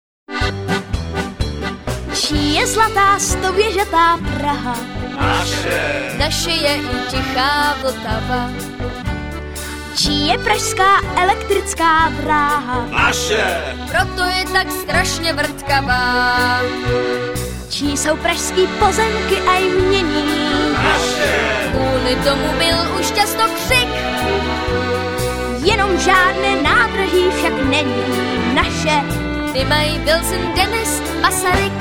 dechová sekce